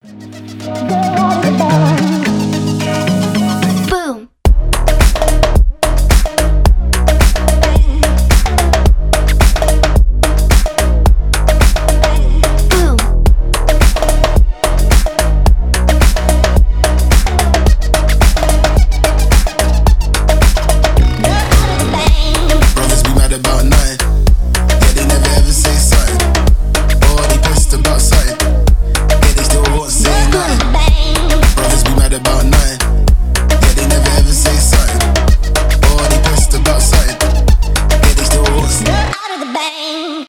клубные
slap house